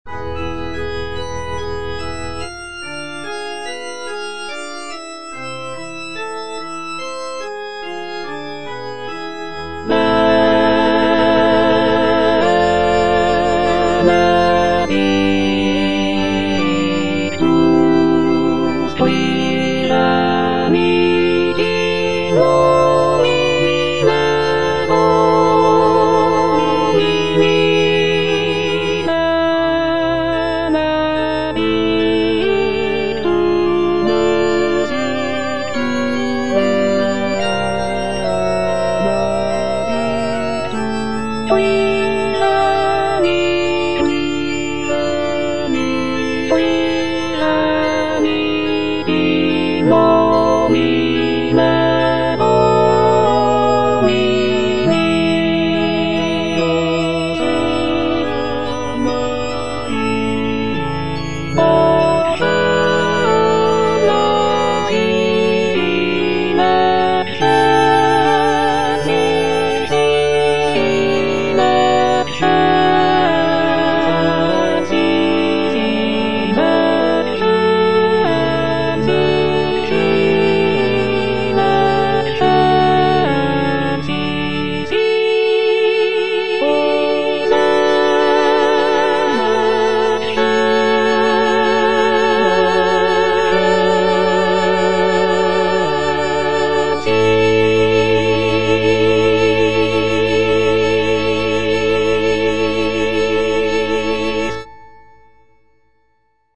J.G. RHEINBERGER - MISSA MISERICORDIAS DOMINI OP.192 Benedictus (choral excerpt only) - Alto (Emphasised voice and other voices) Ads stop: auto-stop
Rheinberger's composition is characterized by rich harmonies, lyrical melodies, and a blend of traditional and innovative elements.